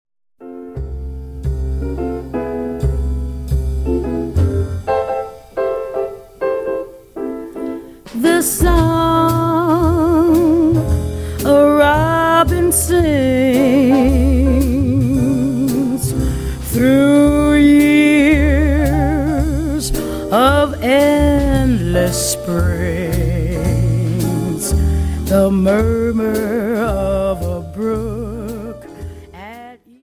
Dance: Slowfox 28